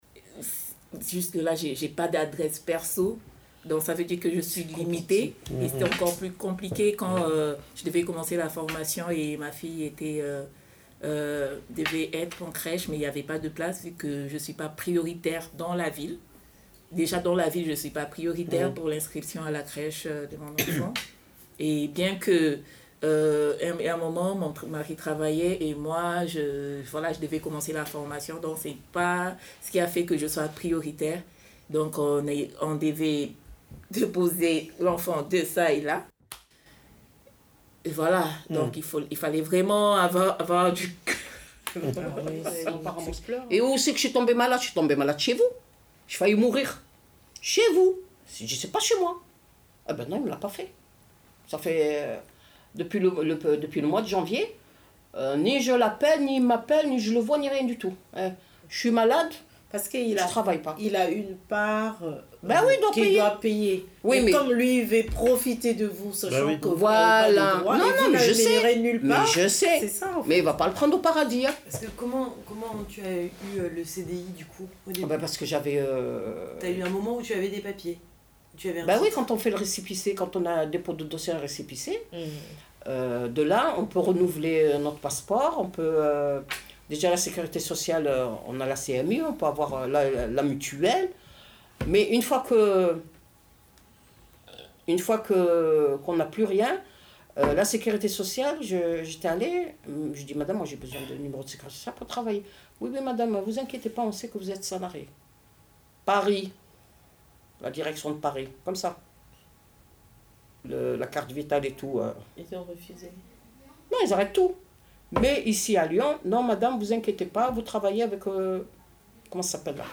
Quelques paroles de personnes confrontées aux difficultés d’accueil des étrangers dans le pays des droits de l’homme.